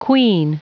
Prononciation du mot queen en anglais (fichier audio)
Prononciation du mot : queen